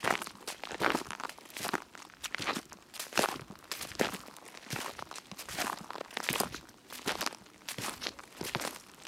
SFX_Footsteps_Gravel_02_Slow.wav